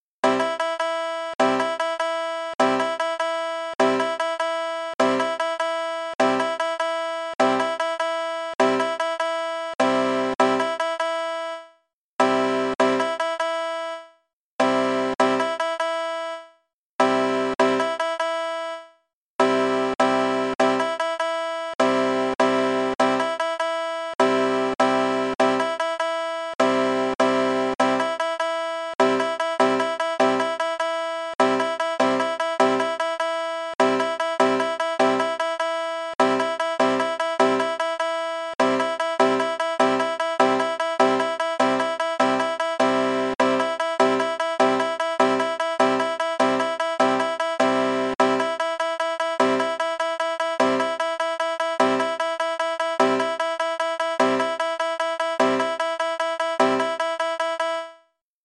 On this webpage, you'll find six banjo exercises illustrating the Triplet Tremolo.
Accent the strokes as indicated.
The sound files below are a plectrum banjo tuned CGBD.
triplet_tremolo_all.mp3